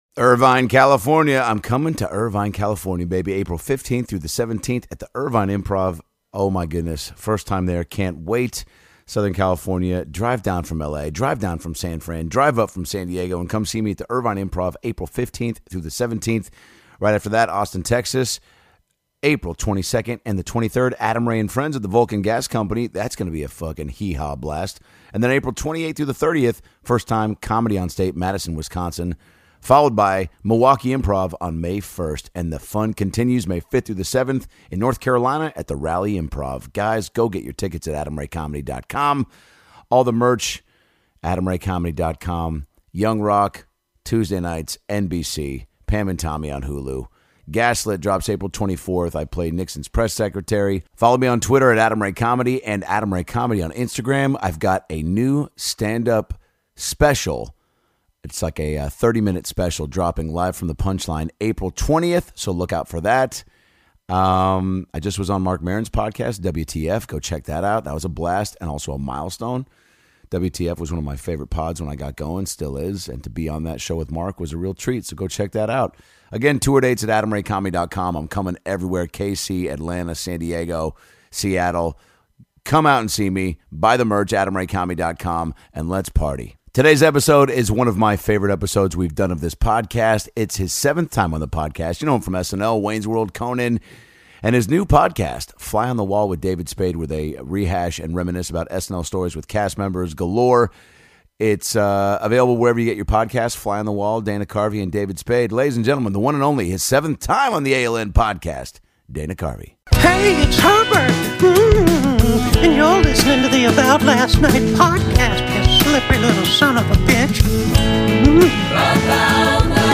In this ALN classic, Dana shares how CHURCH LADY was created on SNL, Wayne’s World at The Oscars, his new podcast with David Spade FLY ON THE WALL, raising kids in the business, Biden, Beatles, and the boys have a hilarious impression off at the end!